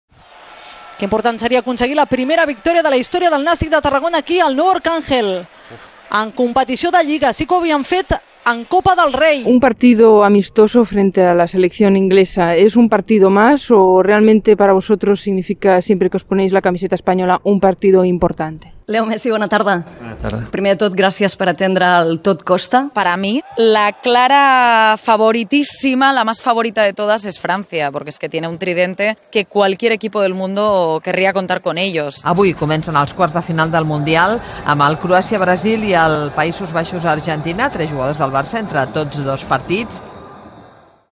Podeu escoltar a continuació una petita selecció de la feina d’algunes d’aquestes professionals de la ràdio esportiva: